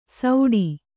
♪ sauḍi